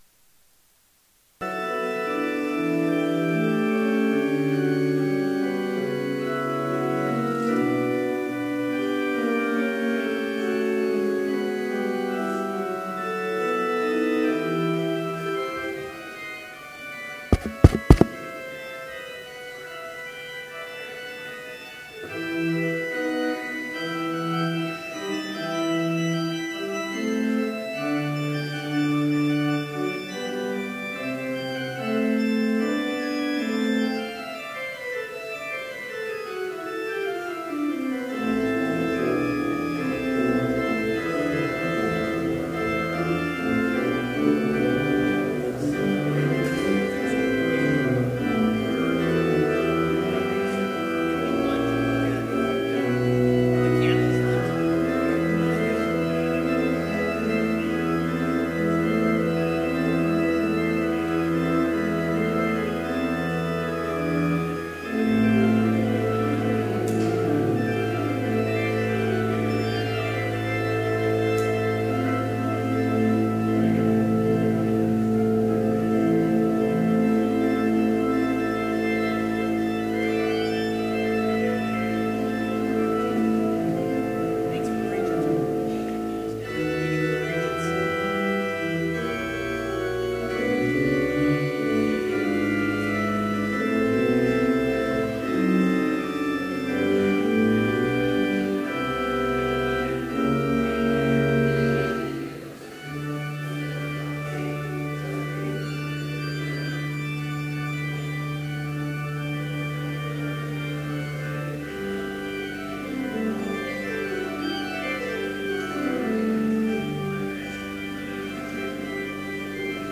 Complete service audio for Chapel - May 9, 2017